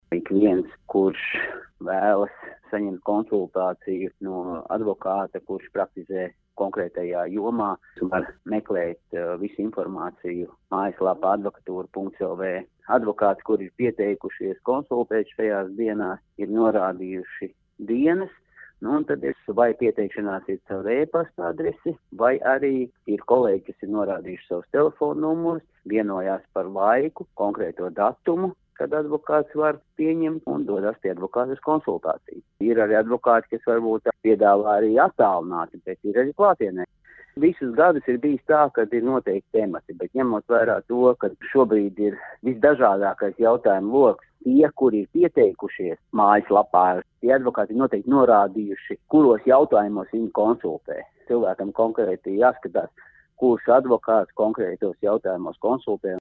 RADIO SKONTO Ziņās par bezmaksas juridiskās palīdzības iespēju